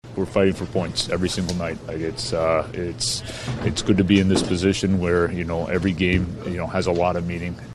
Coach Dan Muse says the opportunity is there for the Pens to make a playoff push.